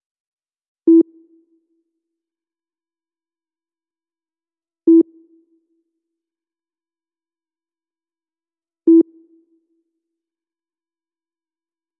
嘟嘟嘟的声音
描述：沉闷的电脑发出哔哔声。使用逻辑合成器插件录制和编辑。
标签： 平淡 计算机 发出蜂鸣声
声道立体声